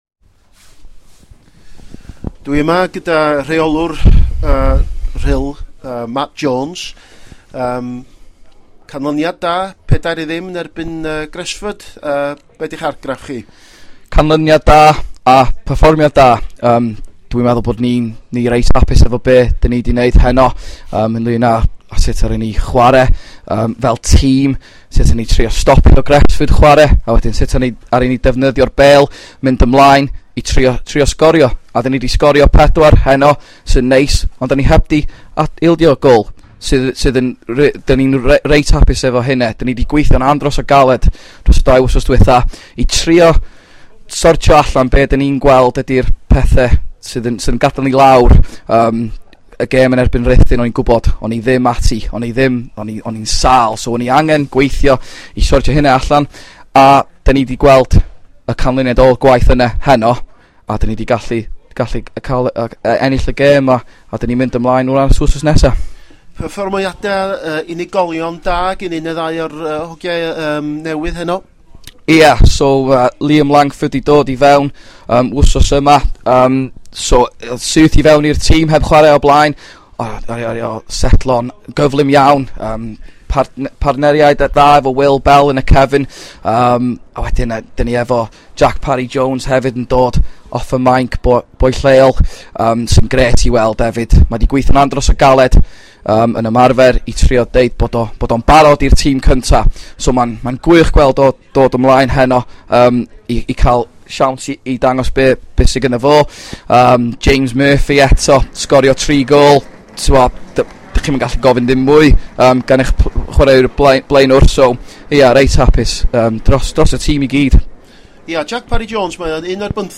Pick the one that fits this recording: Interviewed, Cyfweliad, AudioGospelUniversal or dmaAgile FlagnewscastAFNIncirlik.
Cyfweliad